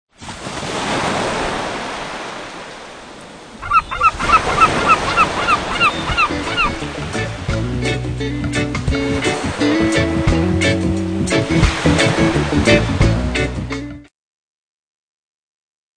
Gagbag - sprechende Tüte